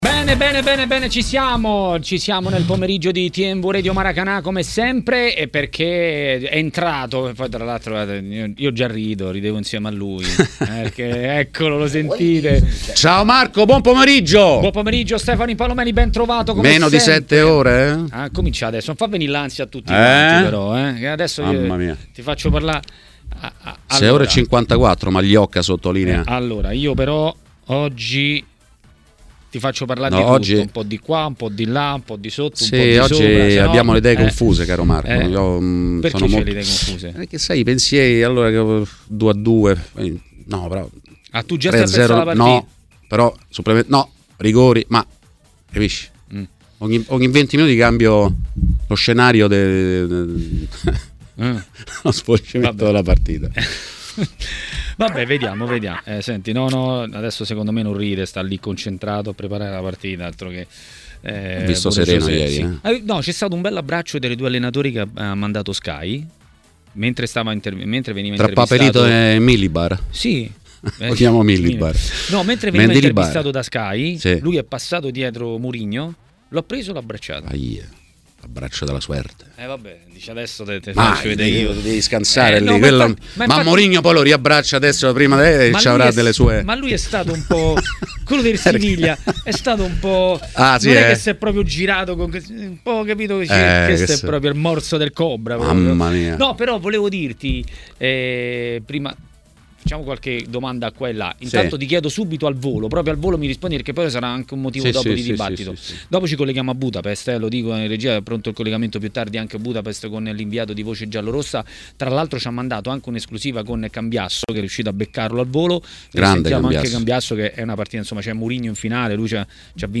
A parlare di Siviglia-Roma e non solo a TMW Radio, durante Maracanà, è stato il giornalista ed ex calciatore Stefano Impallomeni.